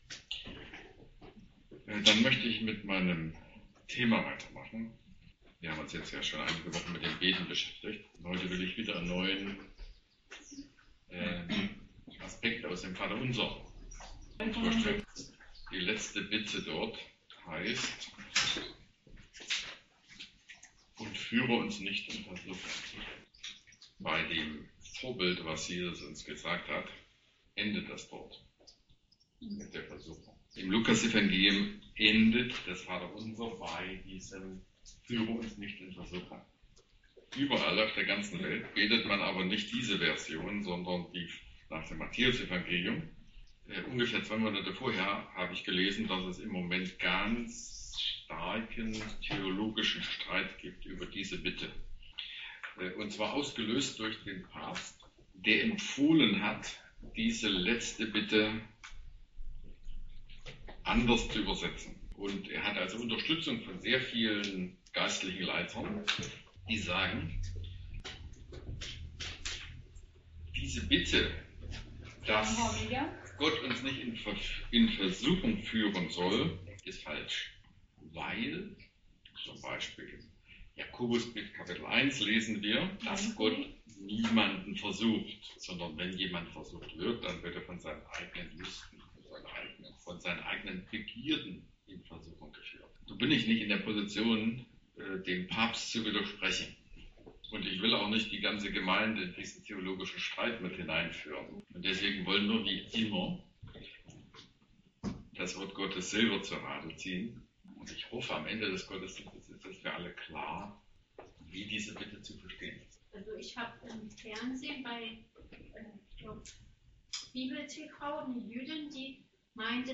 Lukas 11 Vers 4 | Predigt zum Thema: Gebet - Europäische Missionsgemeinschaft